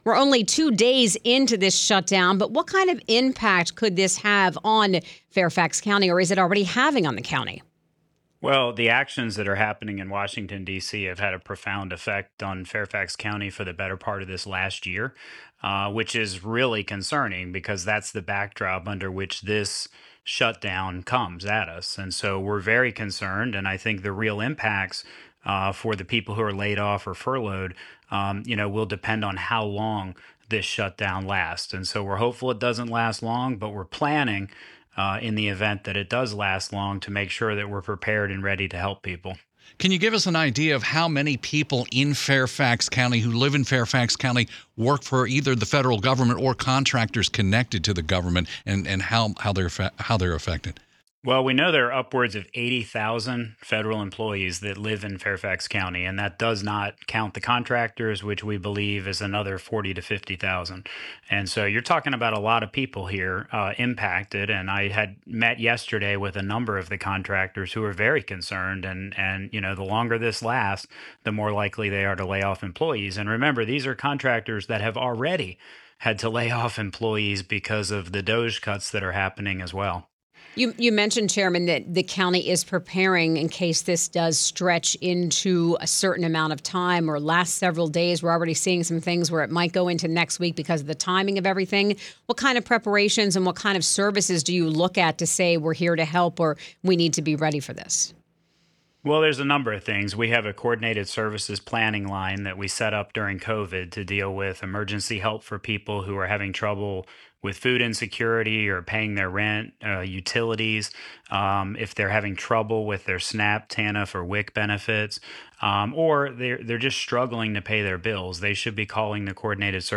mckay-on-wtop.mp3